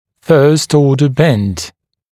[fɜːst-‘ɔːdə bend][фё:ст-‘о:дэ бэнд]изгиб первого порядка